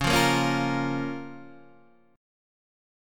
C#6 chord